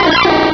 Cri_0211_DP.ogg